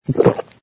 sfx_gulp01.wav